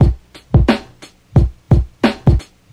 FX (Dillatronic-2).wav